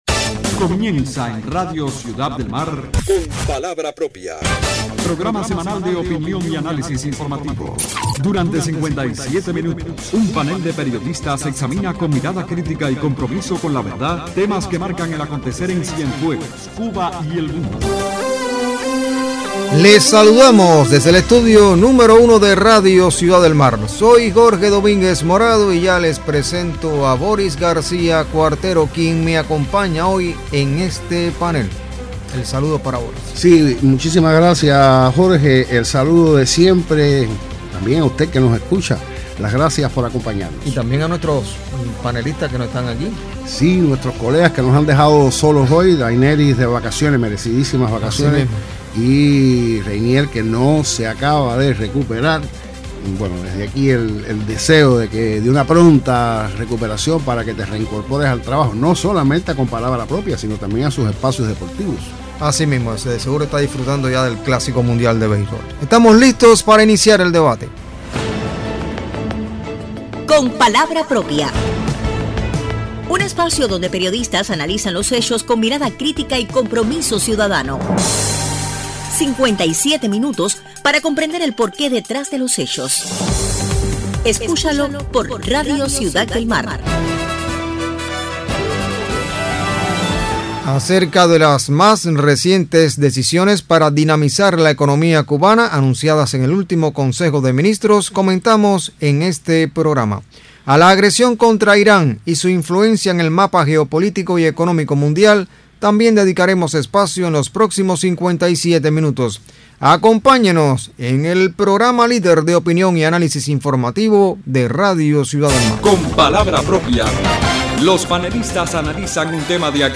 Acerca de las nuevas decisiones adoptadas para dinamizar la economía cubana comentan los panelistas de Con palabra propia en la emisión del 7 de marzo de 2026.